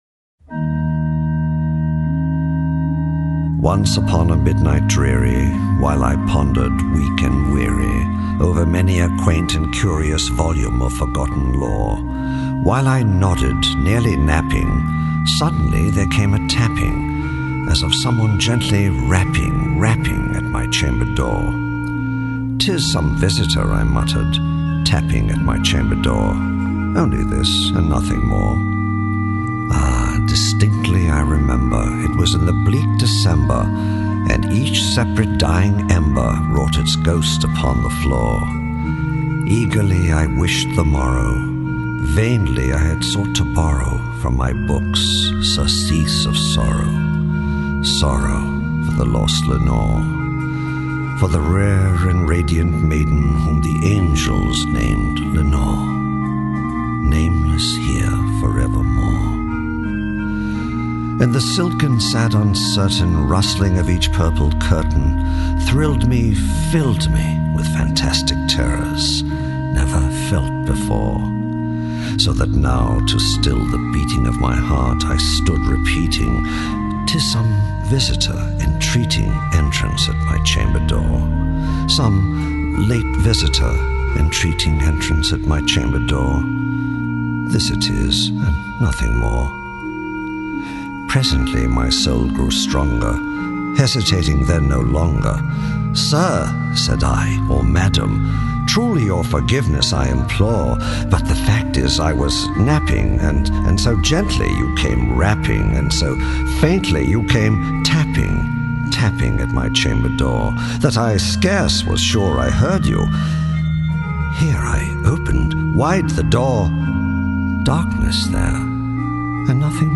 Audio Drama (Entertainment Radio Entertainment Radio